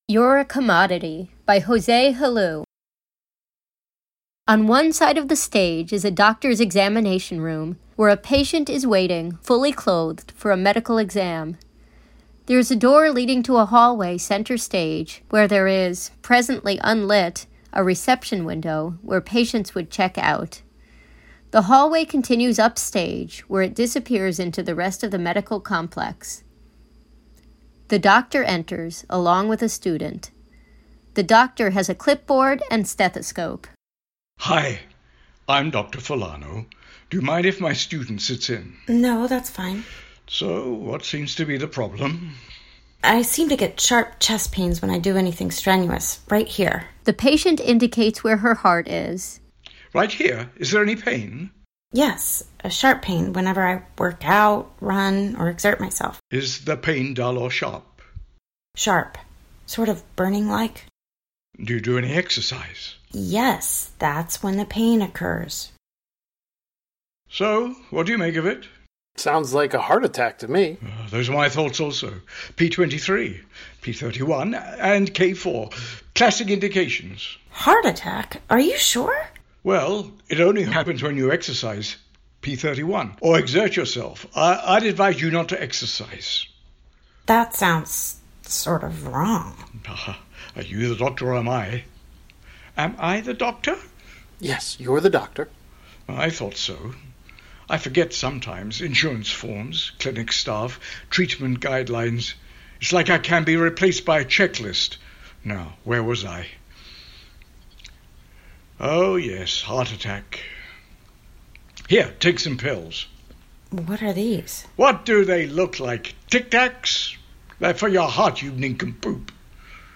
Reading audio:
This recording was generated during the Covid19 pandemic by the Archipelago Virtual Theater. Individual performers voiced their parts separately, offline, and the tracks were edited together into a single performance. The process was iterated three times, ultimately allowing the actors to respond to cues from the other actors who were themselves responding to what other actors were saying.